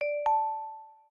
ChargingStarted_Calm.ogg